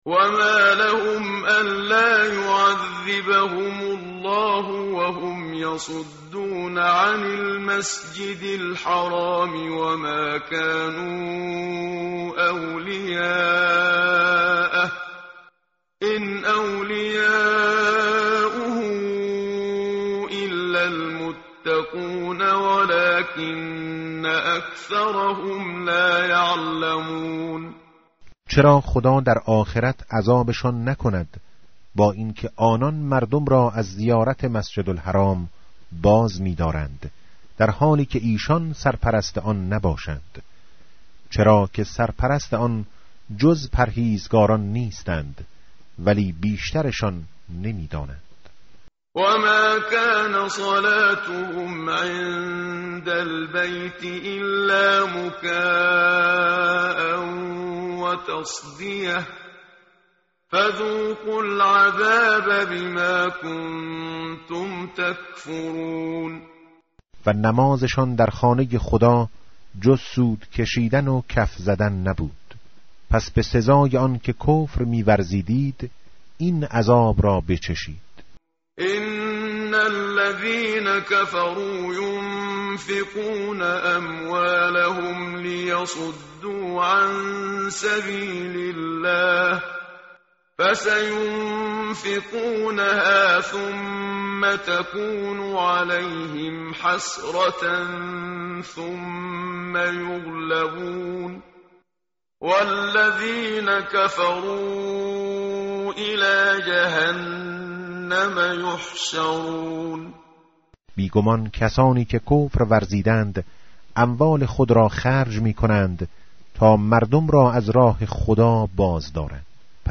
متن قرآن همراه باتلاوت قرآن و ترجمه
tartil_menshavi va tarjome_Page_181.mp3